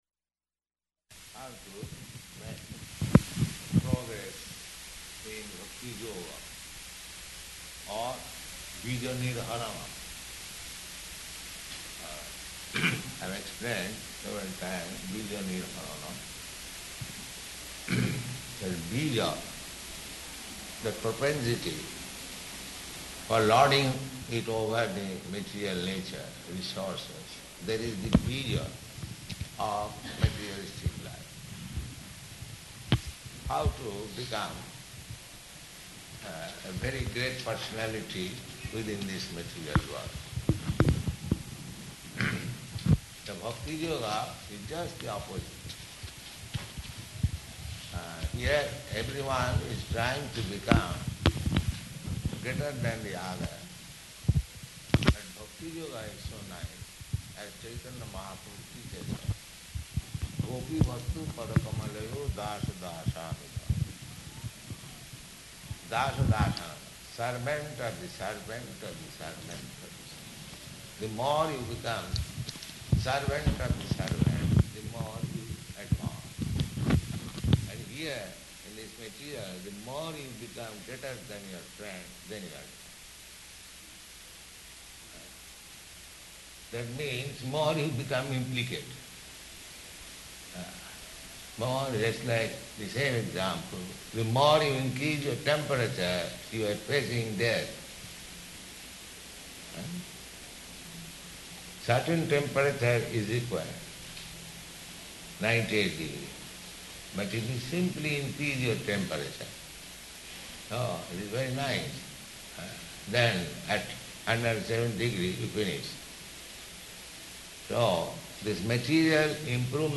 Location: Mombasa